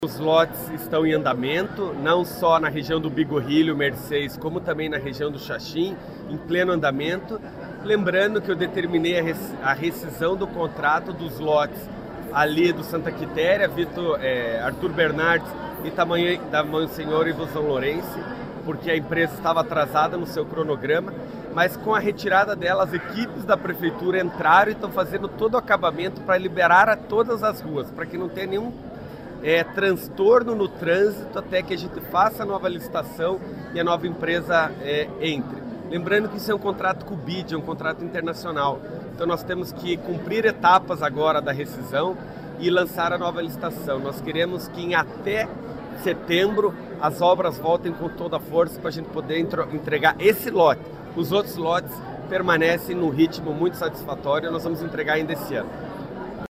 SONORA-PIMENTEL-INTER-2-GJ.mp3